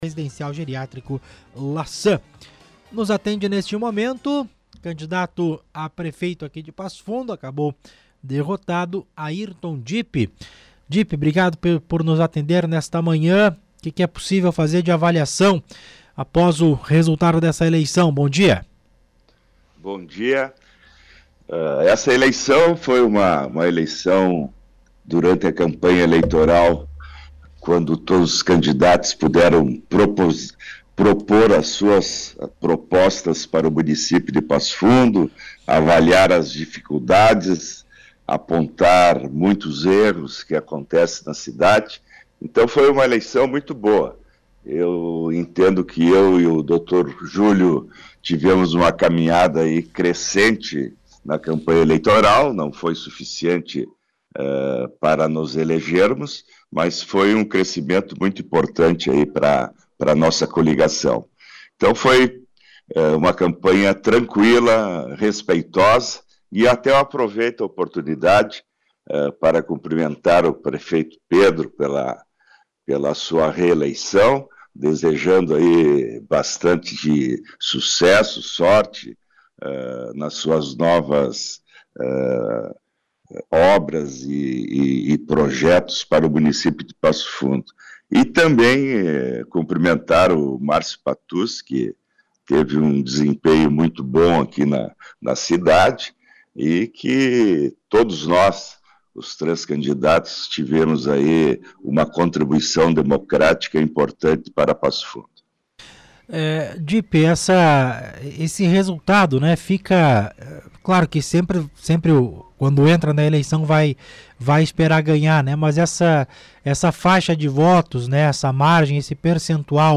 O candidato Airton Dipp (PDT), da Coligação “Sim Passo Fundo Pode Mais” concedeu entrevista nesta segunda-feira, 07, à Rádio Planalto News (92.1) para avaliar a sua participação nas eleições municipais.